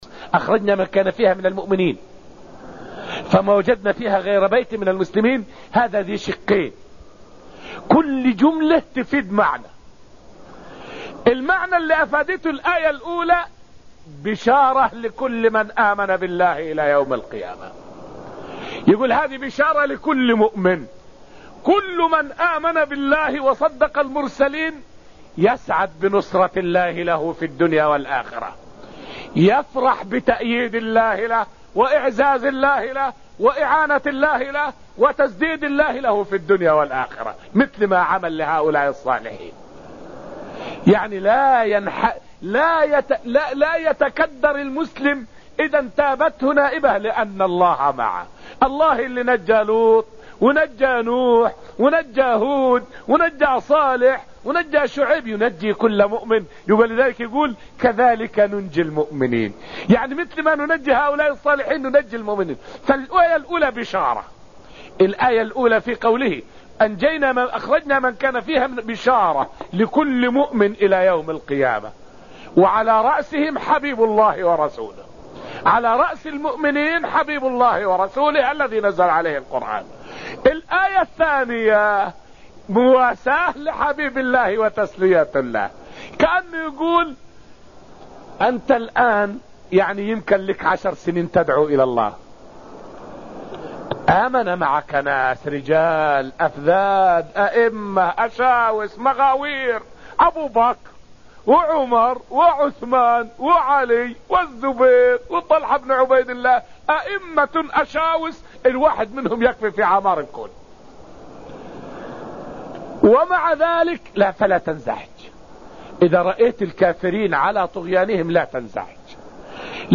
فائدة من الدرس الرابع من دروس تفسير سورة الذاريات والتي ألقيت في المسجد النبوي الشريف حول بشارة ومواساة في قوله تعالى: (فأخرجنا من كان فيها من المؤمنين).